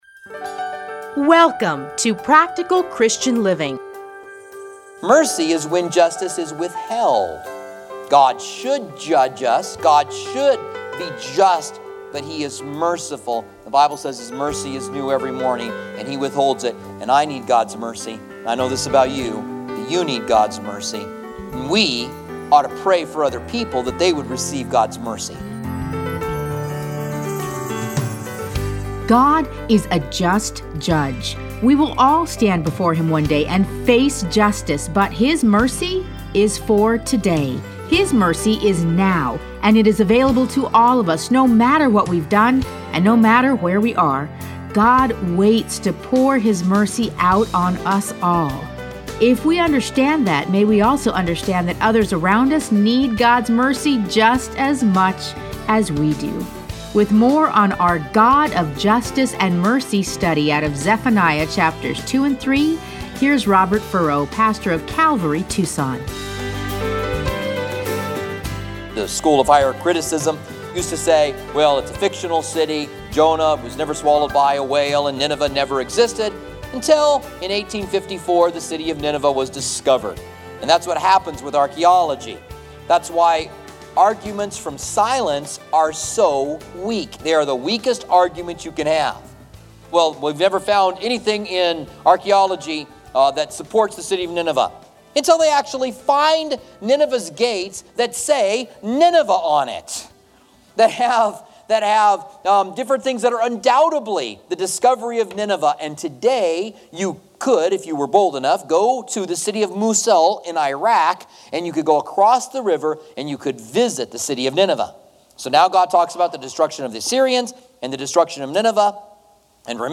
Listen to a teaching from Zephaniah 2-3.